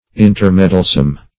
Search Result for " intermeddlesome" : The Collaborative International Dictionary of English v.0.48: Intermeddlesome \In`ter*med"dle*some\, a. Inclined or disposed to intermeddle.